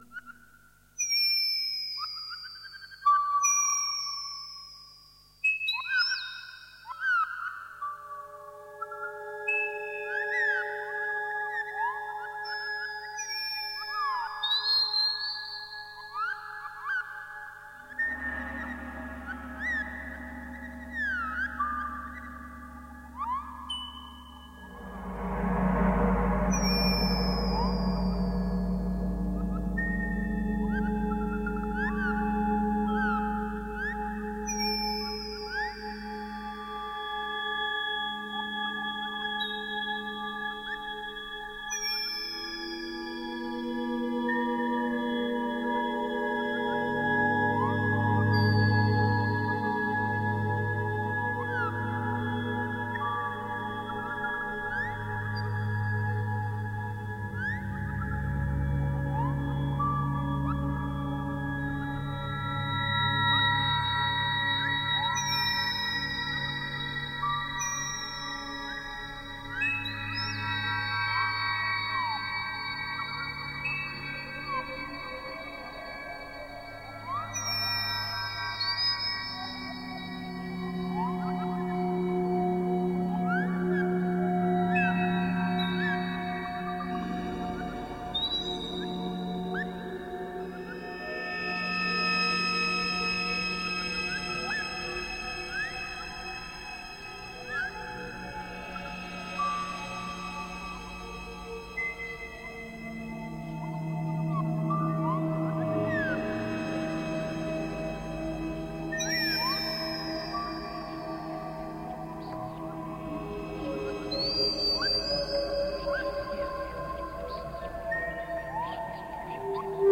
Groundbreaking ambient and dark-ambient.